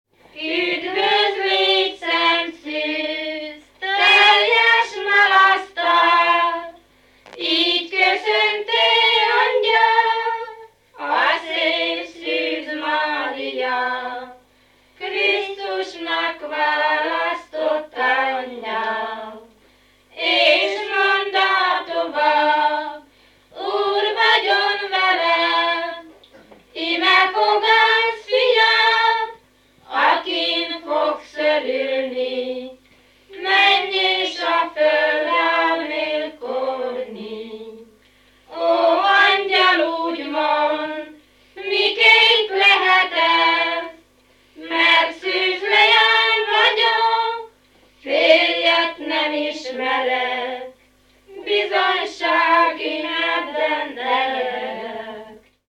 Letölthető a ZTI Publikált népzenei felvételek adatbázisából
Csoport
ének
Váraszó